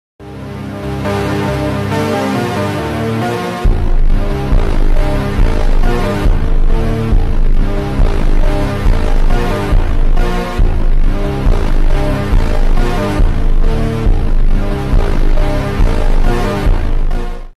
2013 Wayne, NE Ef4 Tornado.